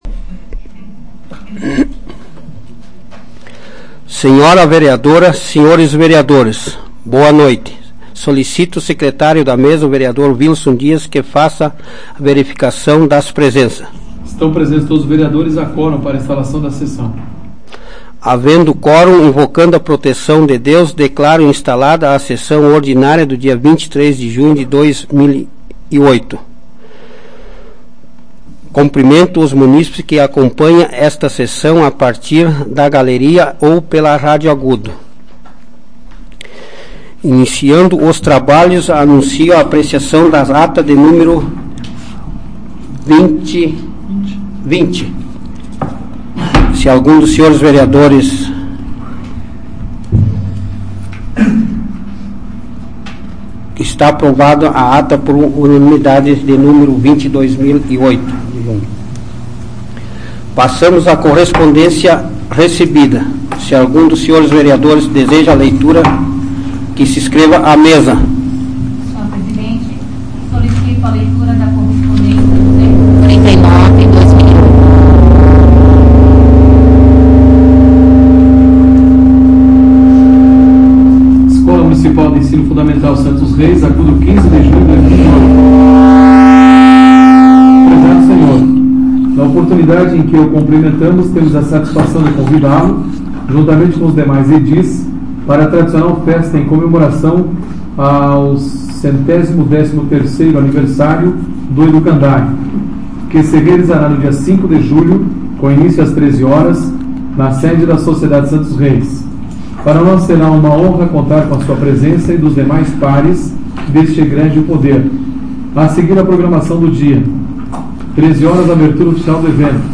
Áudio da 127ª Sessão Plenária Ordinária da 12ª Legislatura, de 23 de junho de 2008